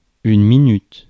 Ääntäminen
Synonyymit seconde mn instant minute d'arc Ääntäminen France: IPA: /mi.nyt/ Haettu sana löytyi näillä lähdekielillä: ranska Käännös Substantiivit 1. minut Muut/tuntemattomat 2. hetk 3. moment Suku: f .